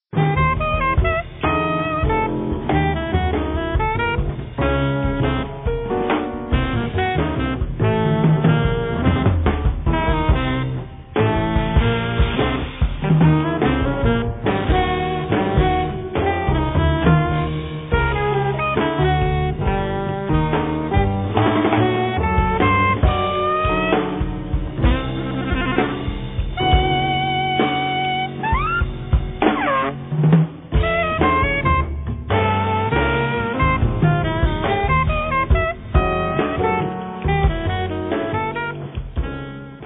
alto sax, clarinetto, clarinetto basso
piano, tastiere, laptop
contrabbasso
batteria, vari oggetti
I temi sono spigolosi, pungenti